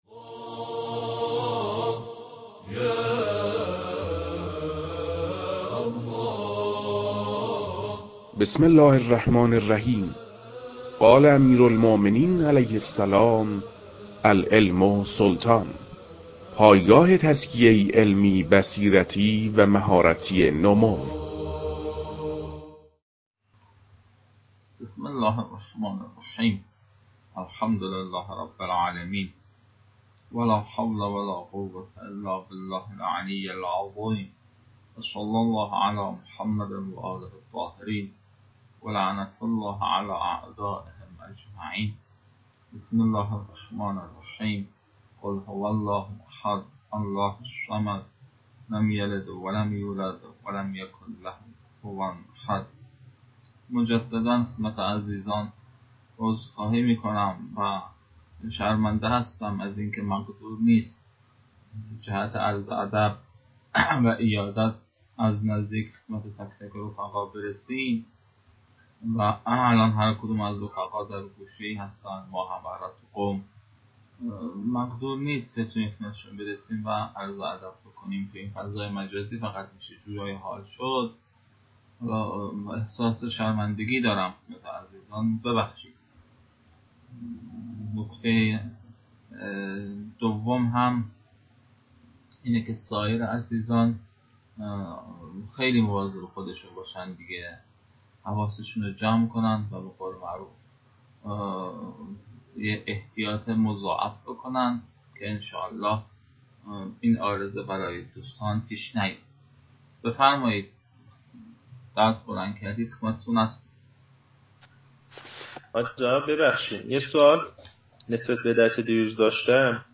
در آغاز این فایل به پاسخ سؤالات طلاب در مورد مبحث قبلی پرداخته شده‌است.